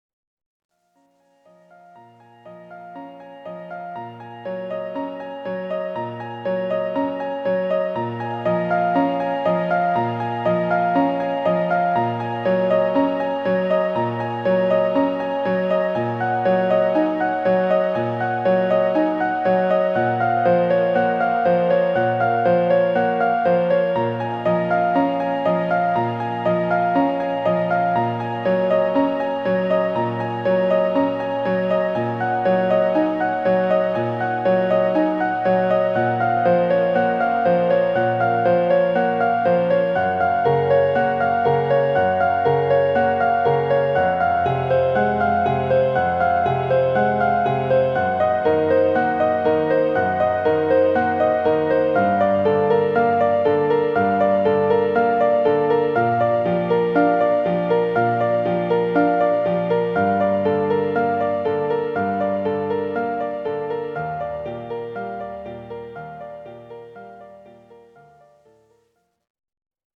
Relaxációs cd 50 perc zenével.